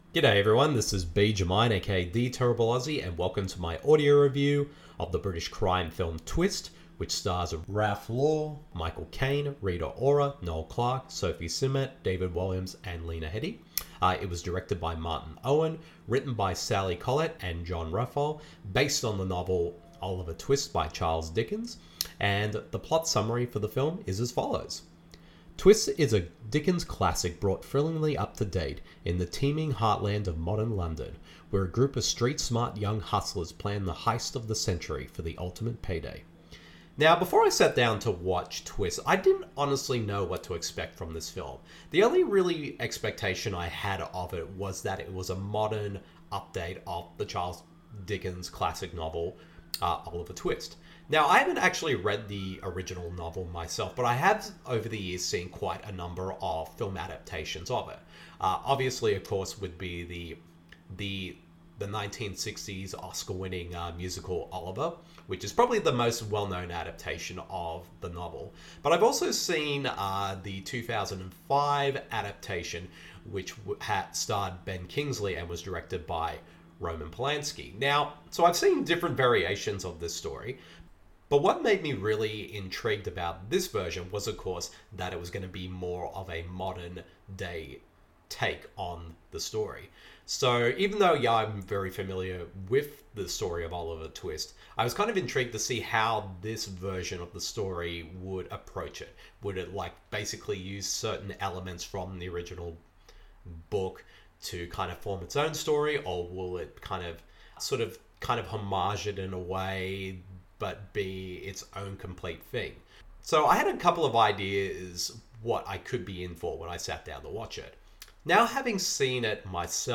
The latest one to join this group is the 2021 film TWIST, which adapts the novel of OLIVER TWIST into a fast-paced modern day British crime film. The following review of the film is in an audio format.